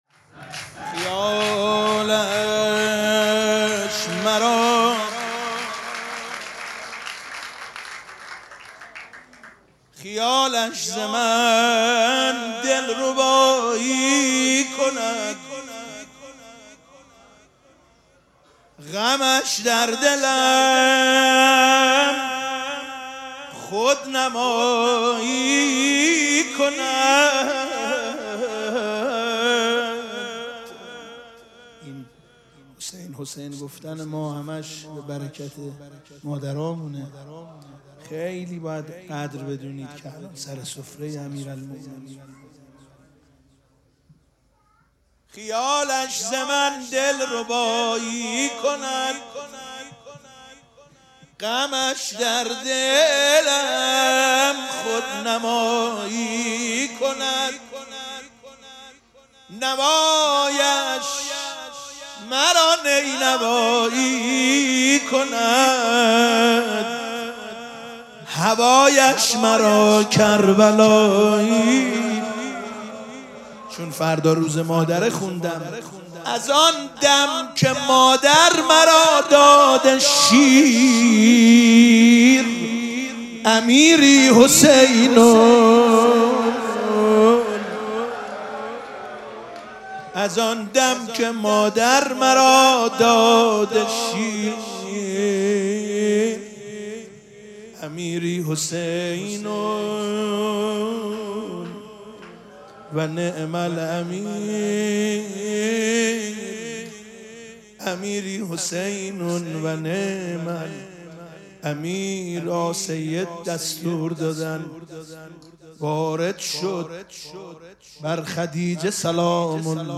مراسم جشن ولادت حضرت زهرا سلام الله علیها
مدح